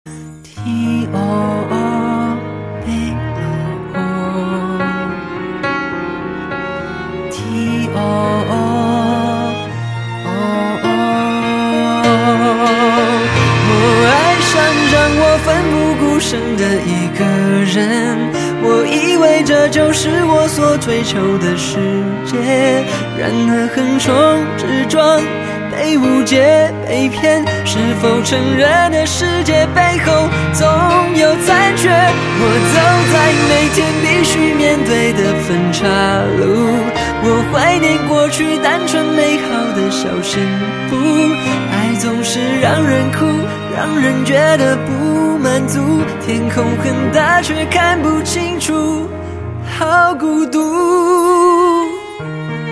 M4R铃声, MP3铃声, 华语歌曲 82 首发日期：2018-05-15 12:42 星期二